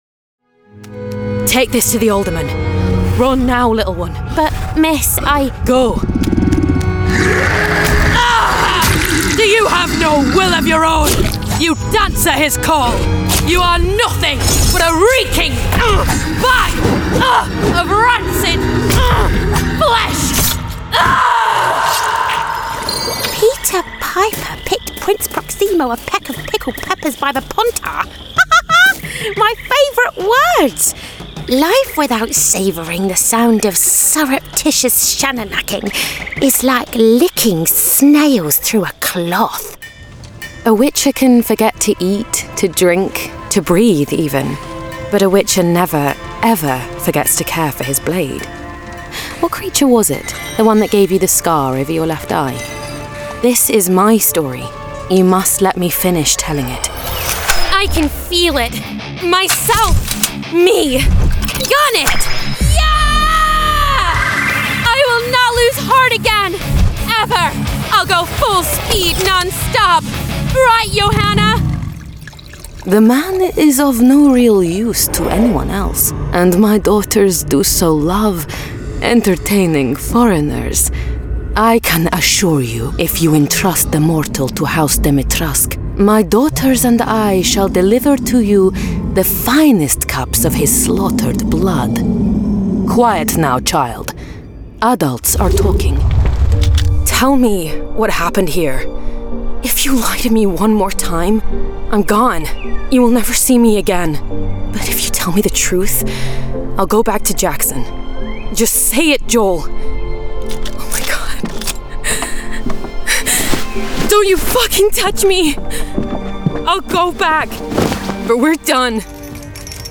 Video Game Reel
• Native Accent: RP
• Home Studio